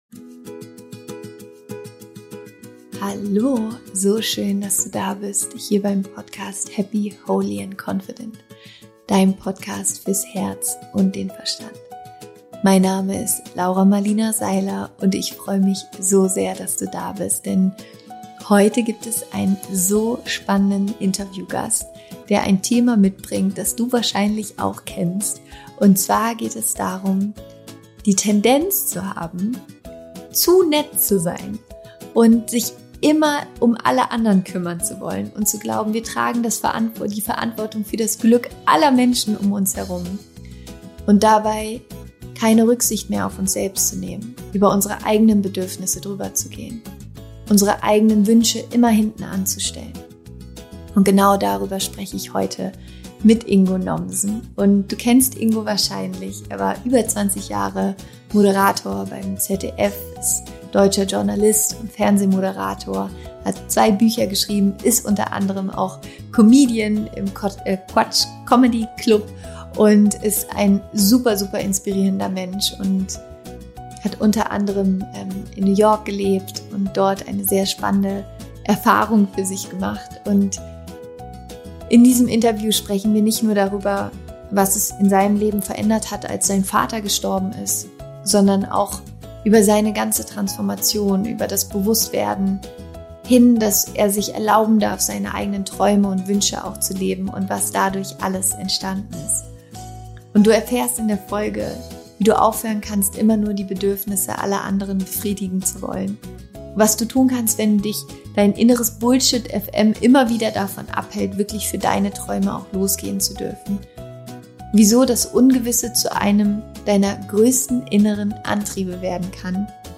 Fühlst du dich immer für das Glück aller verantwortlich? - Interview Special mit Ingo Nommsen
Fühlst du dich immer für das Glück anderer verantwortlich? Da bist du nicht allein ;) Genau darüber spreche ich im Podcast mit meinem Gast Ingo Nommsen!